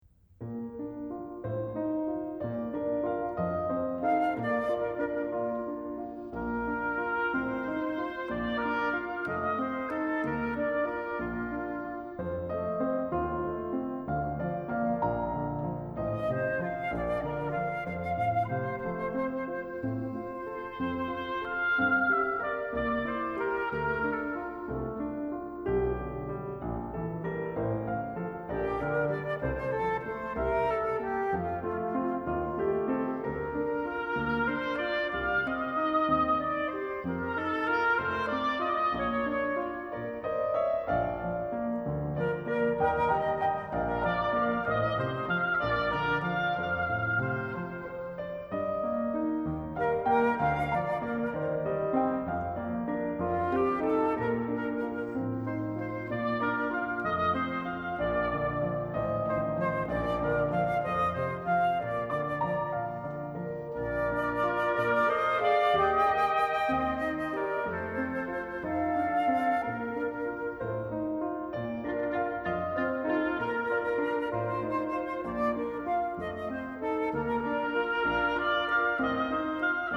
flute
oboe